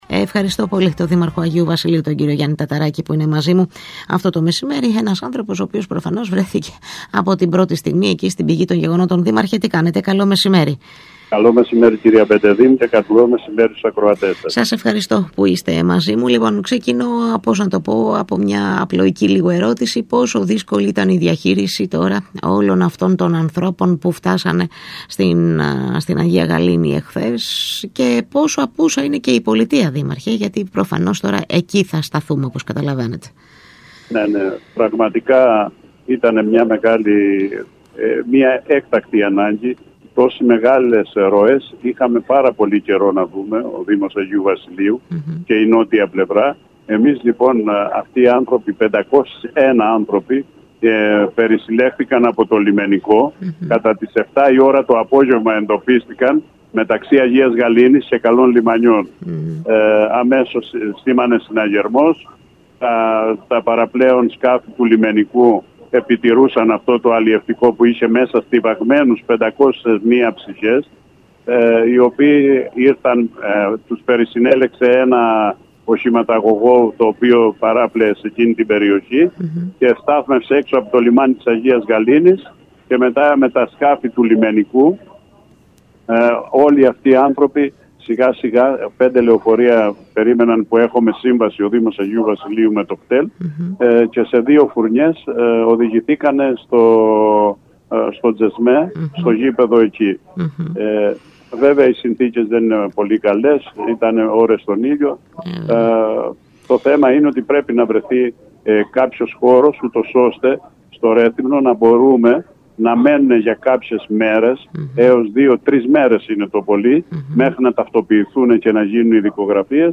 Καταδίκασε τις αντιδράσεις μερίδας πολιτών για την προσωρινή φιλοξενία των 501 μεταναστών στο γήπεδο του Τσεσμέ στο Ρέθυμνο, ο Δήμαρχος Αγίου Βασιλείου Γιάννης Ταταράκης, μιλώντας στον ΣΚΑΪ Κρήτης 92.1